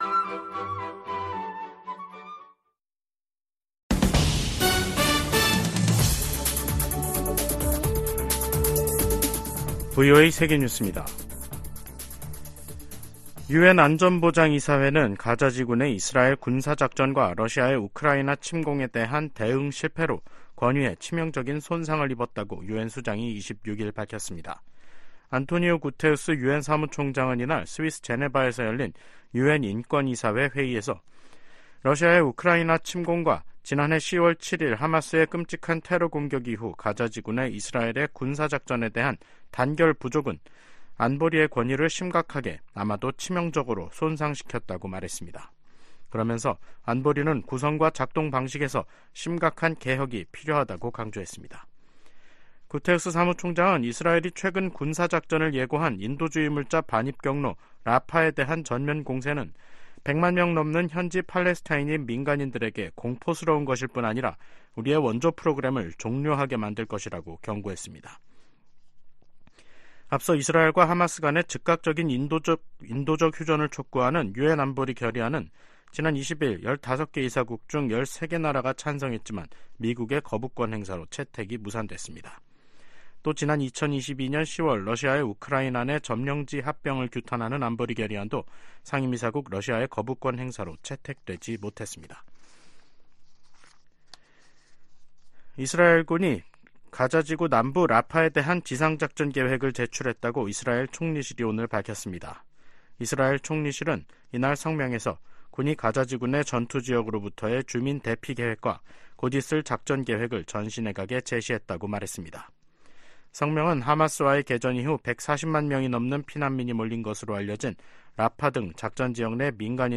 세계 뉴스와 함께 미국의 모든 것을 소개하는 '생방송 여기는 워싱턴입니다', 2024년 2월 26일 저녁 방송입니다. '지구촌 오늘'에서는 러시아와의 전쟁에서 우크라이나 전사자가 3만1천 명이라고 볼로디미르 젤렌스키 대통령이 밝힌 소식 전해드리고, '아메리카 나우'에서는 도널드 트럼프 전 대통령이 사우스캐롤라이나 공화당 경선에서 또 다시 승리한 이야기 살펴보겠습니다.